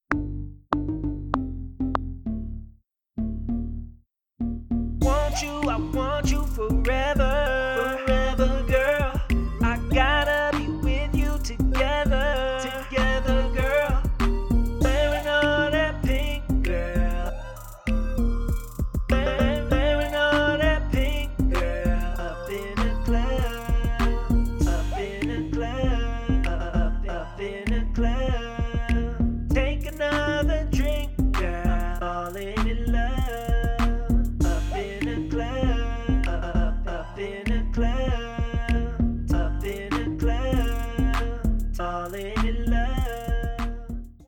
Radio rnb Virtual Instrument 就在这里。将现代嘻哈带到电台。Radio RnB 是最神奇的虚拟乐器，其中包含杀死收音机所需的声音。由 kontakt 5 发动机提供动力。涵盖 8 种乐器类别，一定会让您的节拍具有克里斯·布朗、特雷·松兹、Dj Mustard 类型的声音。
Bass
Keys
Pads
Plucked Instruments